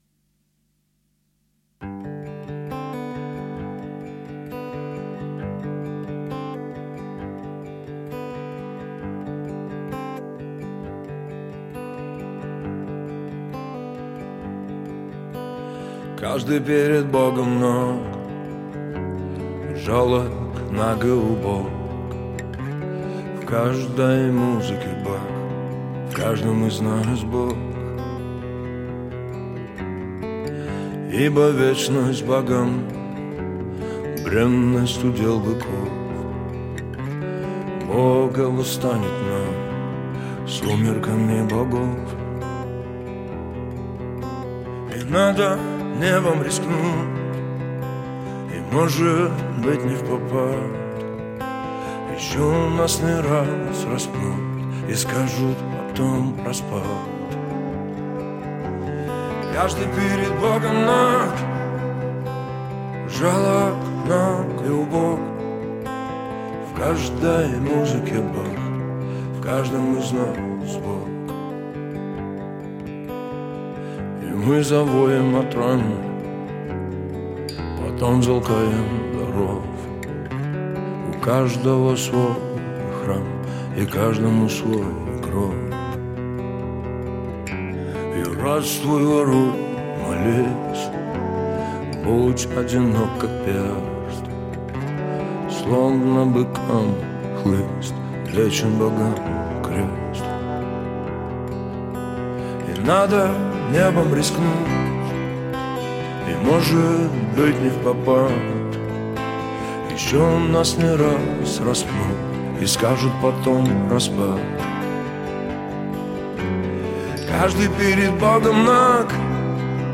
Жанр: Жанры / Альтернатива